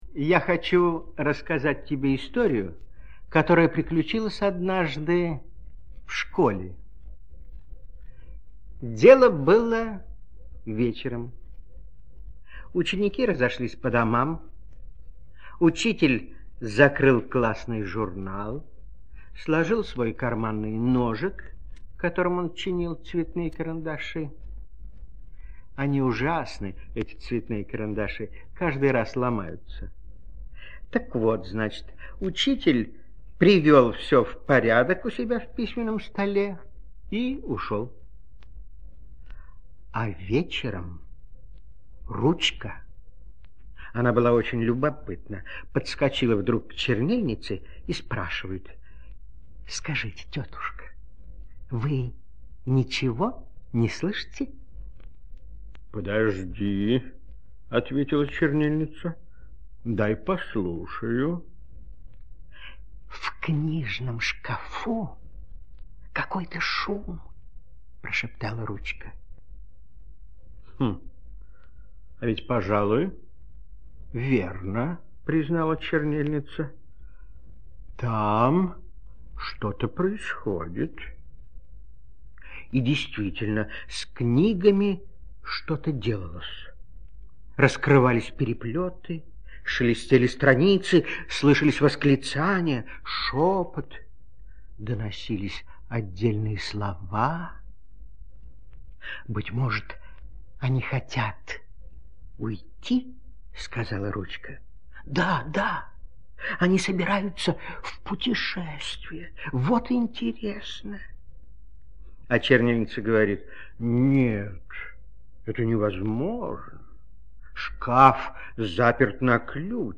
Волшебные слова - аудиосказка Гамарра - слушать онлайн
Текст читает Литвинов Н.